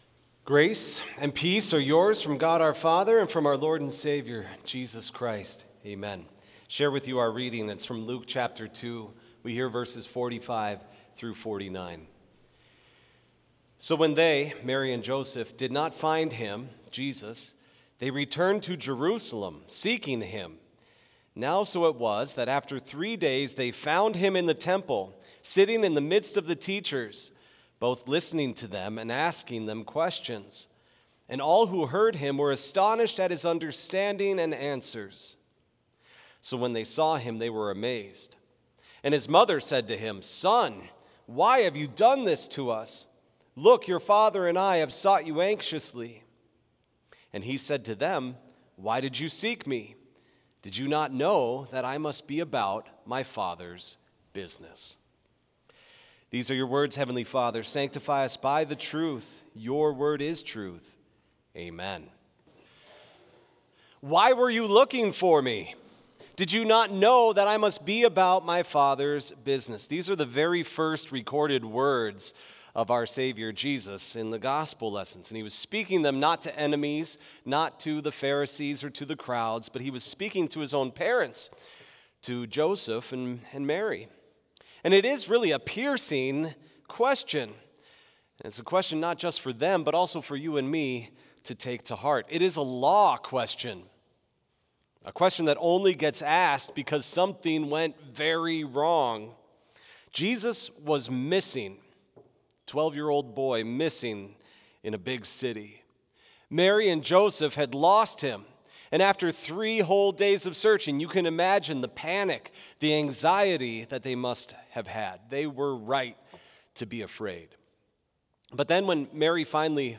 Complete service audio for Chapel - Thursday, January 15, 2026
Sermon Only Order of Service Prelude Hymn 81 - O Splendor of God's Glory Bright View vv. 1 - 5 Reading